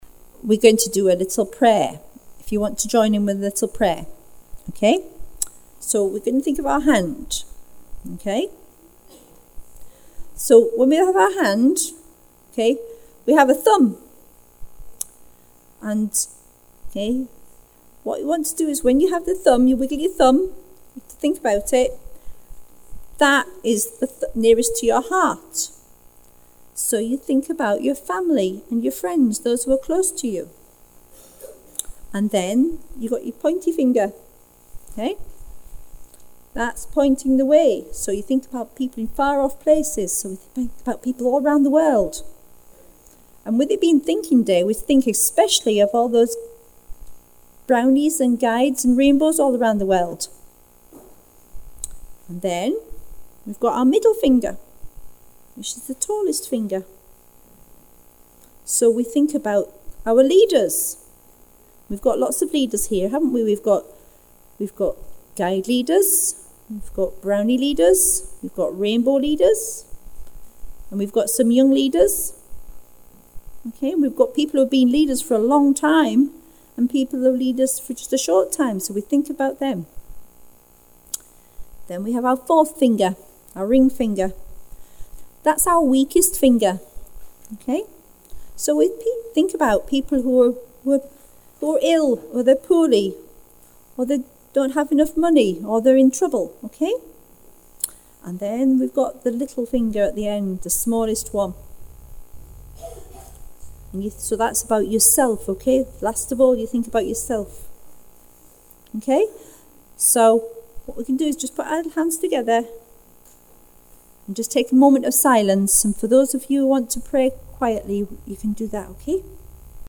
Thinking Day - 19 February 2019
Hand Prayer' the girls all joined in 'We’re altogether again, we’re here, we’re here.